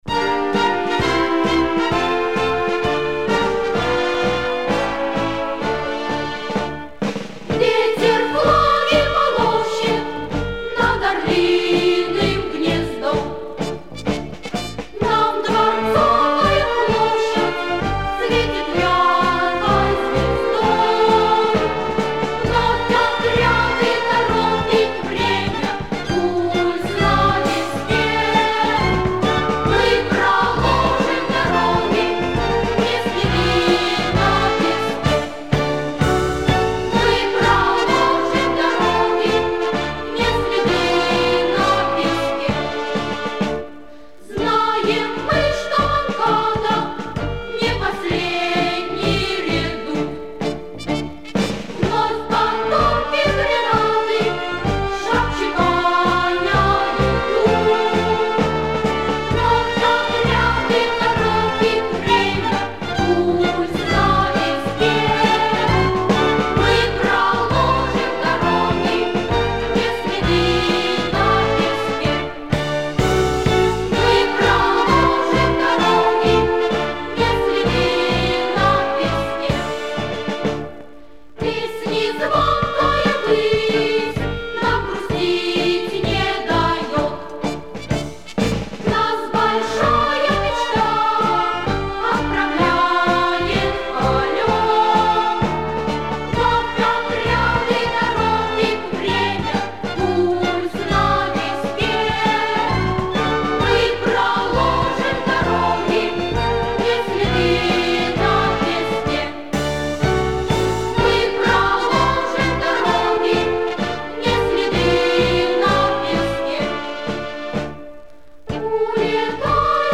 Источник собственная оцифровка